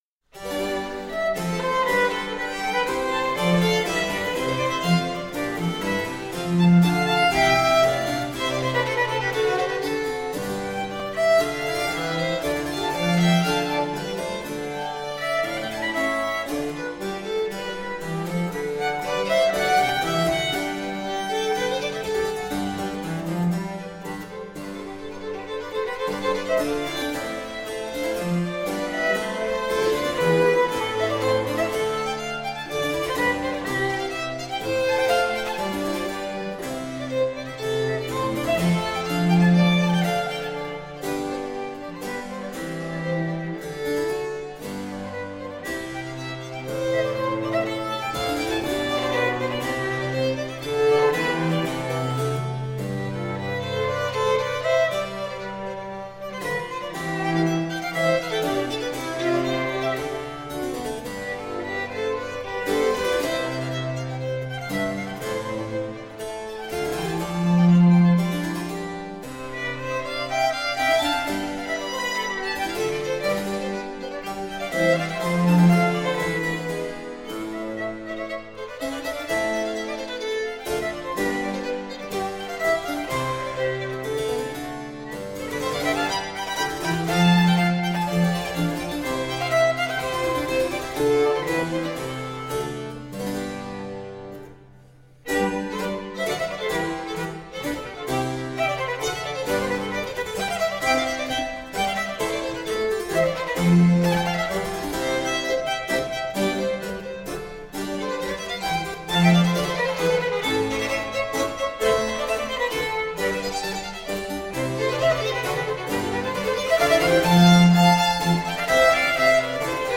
17th century baroque ensemble.
Classical, Orchestral, Baroque